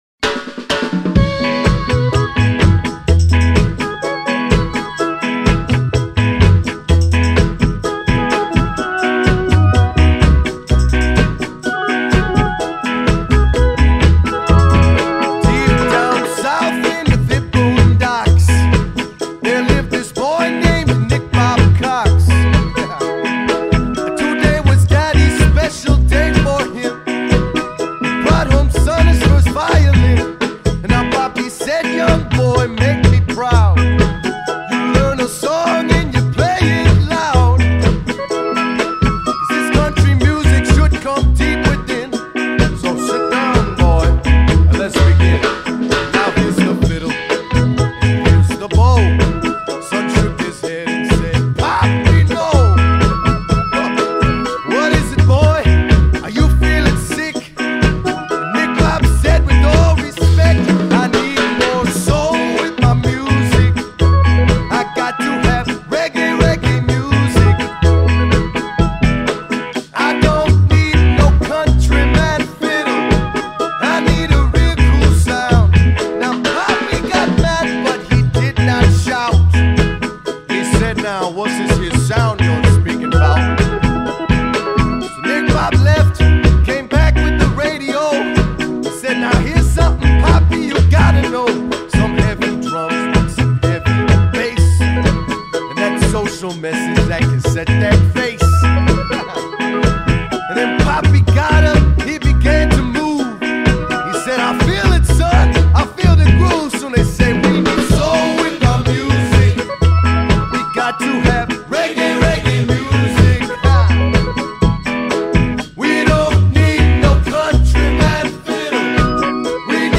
Reggae and Ska